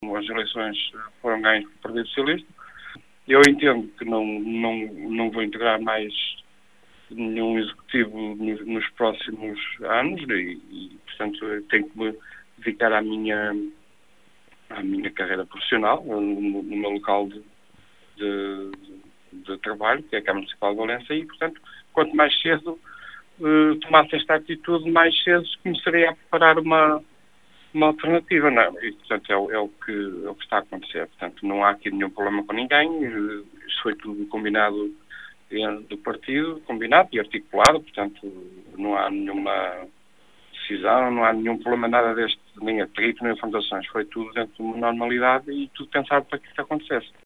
Entrevistado pela Rádio Caminha , Mário Patrício diz que não faz sentido continuar depois da derrota nas últimas eleições, e entende que é altura de dar lugar a outros.